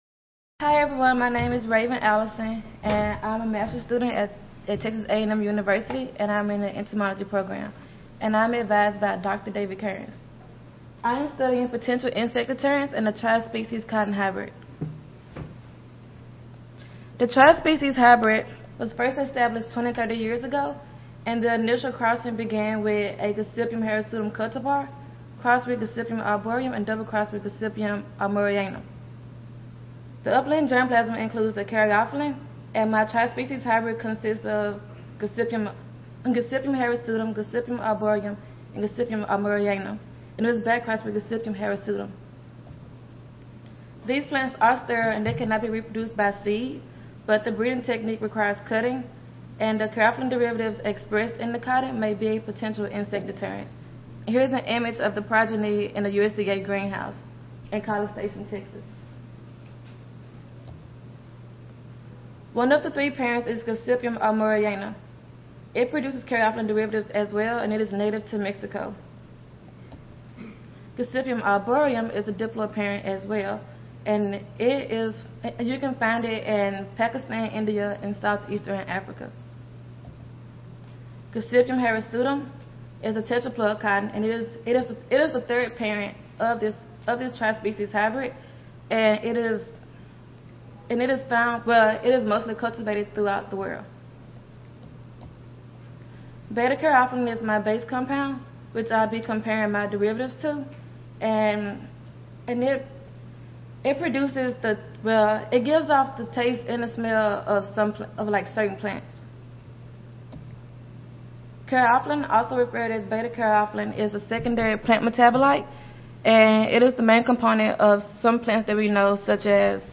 Audio File Recorded Presentation